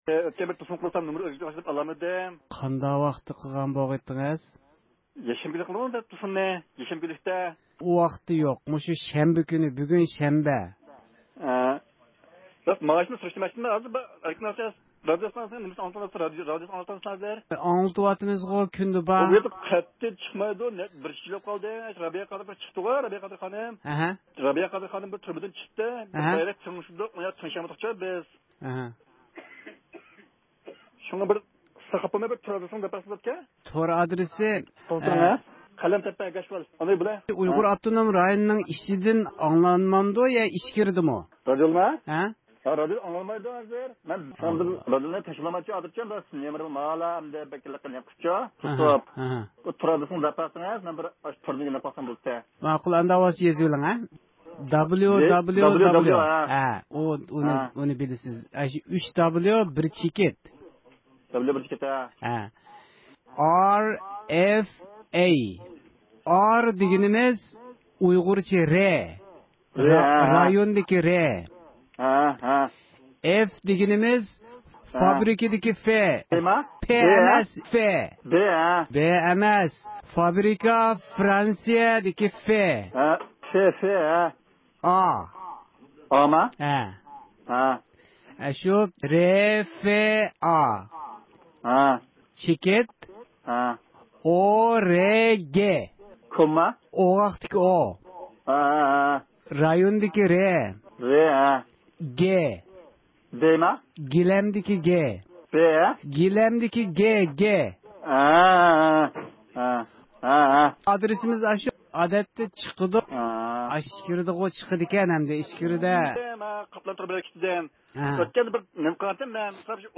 ئەركىن ئاسىيا رادىئوسىنىڭ ھەقسىز تېلېفون لېنىيىسى ئارقىلىق تېلېفون قىلغان بۇ ئۇيغۇرنىڭ بايانلىرىدىن مەلۇم بولۇشىچە، ھازىر خىتاي ھۆكۈمىتىنىڭ ئۇيغۇرلارغا قاراتقان سىياسەتلىرى ناچار، ئۇيغۇرلارنىڭ تۇرمۇشى ياخشى ئەمەس، ئەمما ئۇيغۇر يۇرتلىرىغا كەلگەن خىتايلار بېيىپ كەتكەن.